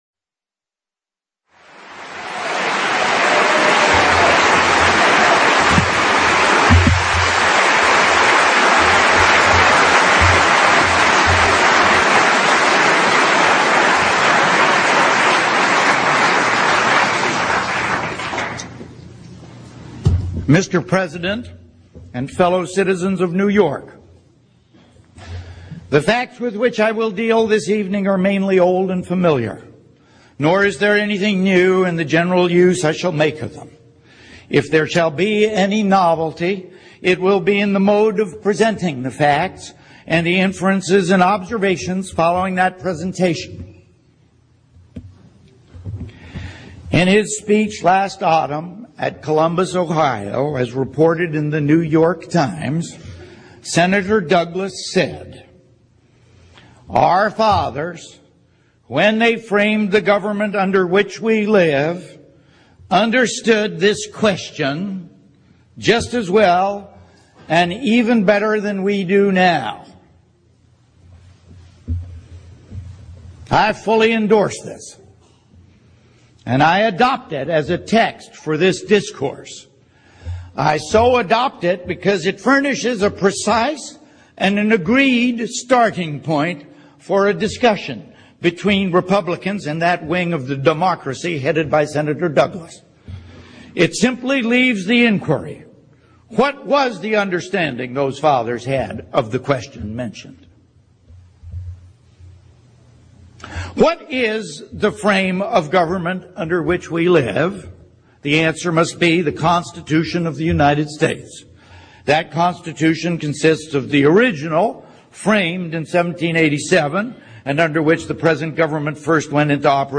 Close behind is Lincoln’s speech at Cooper Union – here is a recreation of it by Sam Waterston (and here is the text).
samwaterstoncooperunion.mp3